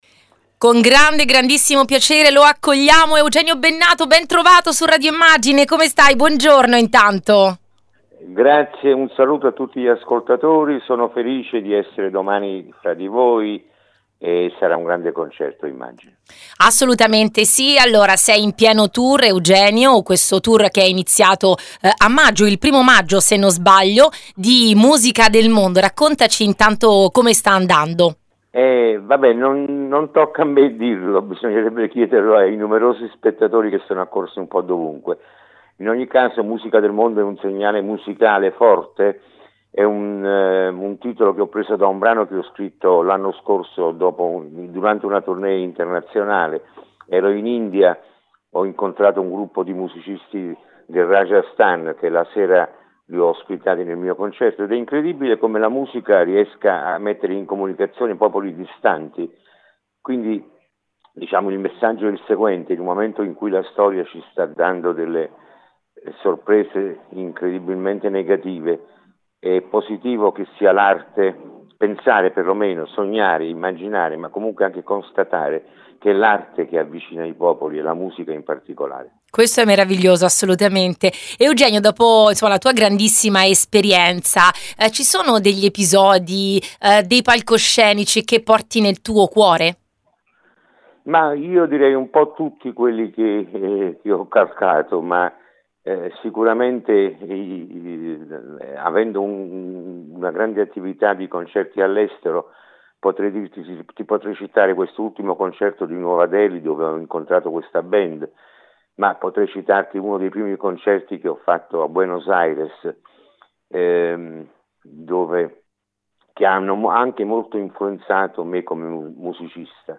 Durante le ultime prove prima di domani abbiamo intervistato il Maestro Eugenio Bennato